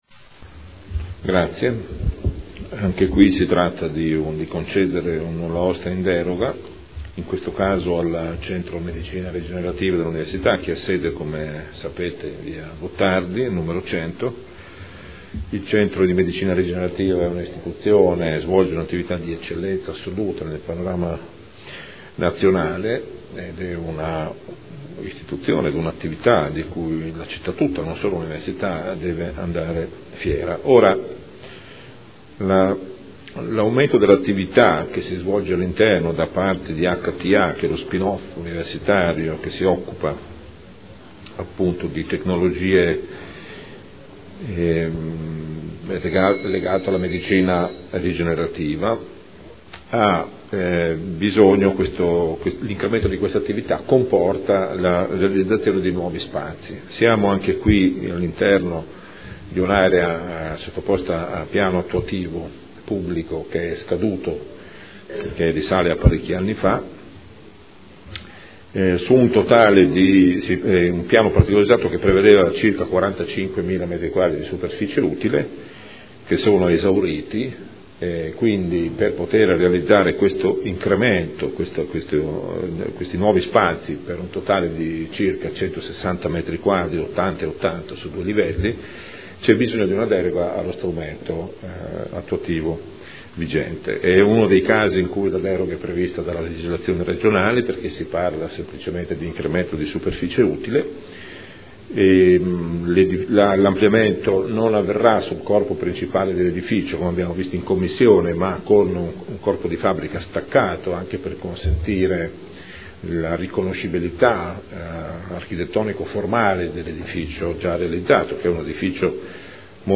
Seduta del 31 marzo. Proposta di deliberazione: Proposta di progetto - Ampliamento del Centro Medicina Rigenerativa – Via Gottardi – Z.E. 473 area 01 – Nulla osta in deroga agli strumenti urbanistici comunali – Art. 20 L.R. 15/2013